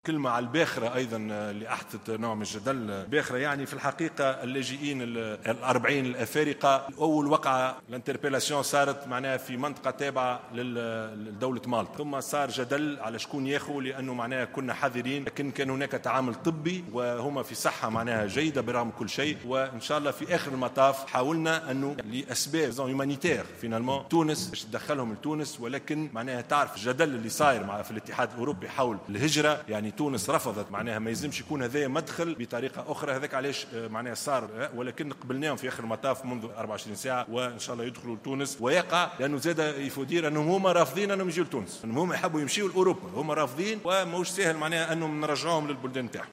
وجاء هذا الإعلان على هامش رد رئيس الحكومة على أسئلة النواب في جلسة عامة خصصت لمنح الثقة لوزير الداخلية الجديد.